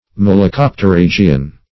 Malacopterygian \Mal`a*cop`ter*yg"i*an\, n. [Cf. F.